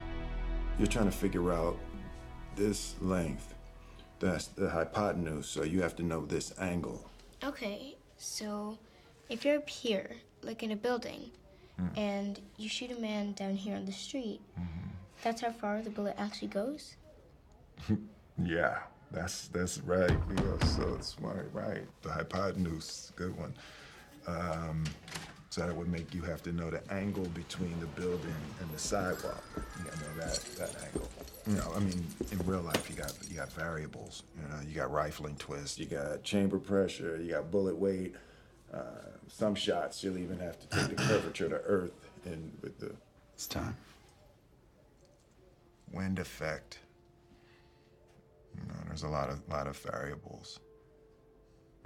In the movie Suicide Squad (2016) "Deadshot" played by Will Smith does some trigonometry with his daughter.